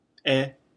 “e” is pronounced “eh” like the “e” in “egg” or “elephant”
e-hiragana.mp3